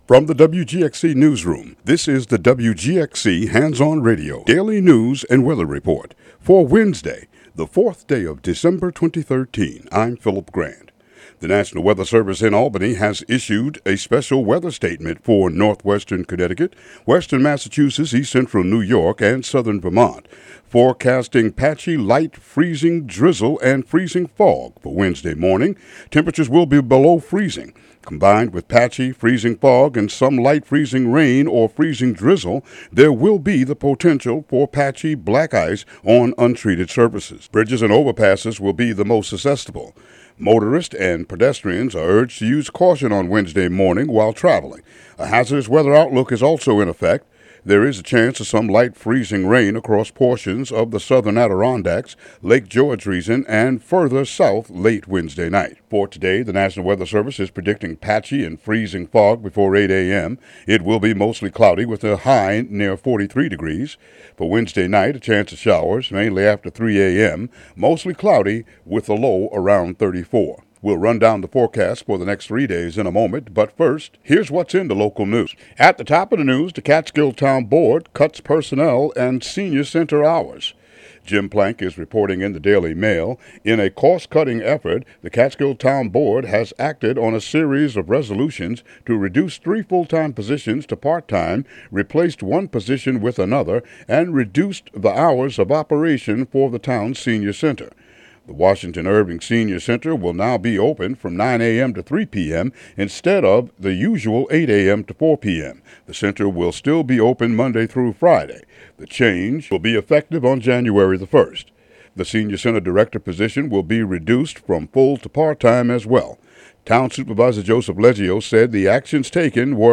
Local news and weather for December 4, 2013.